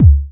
Index of /90_sSampleCDs/USB Soundscan vol.11 - Drums Dance & Groove [AKAI] 1CD/Partition B/01-ALL BD 1